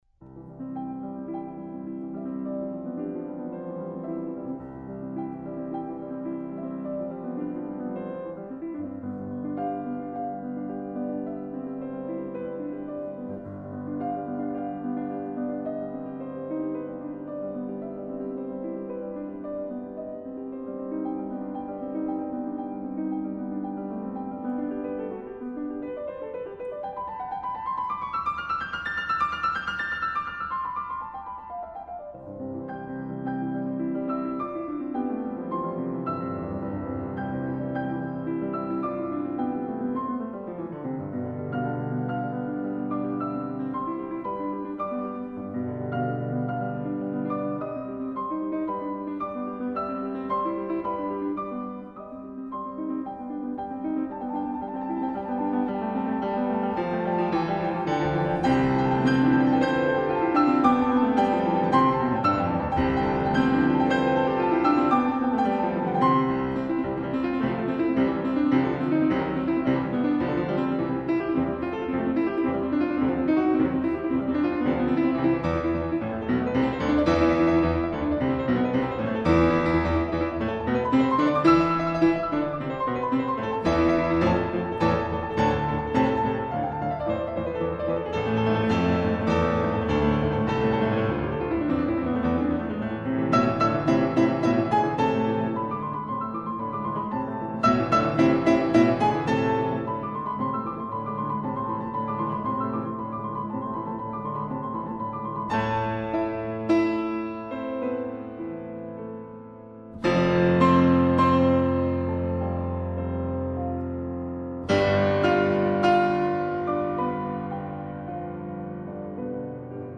transparent and effortless playing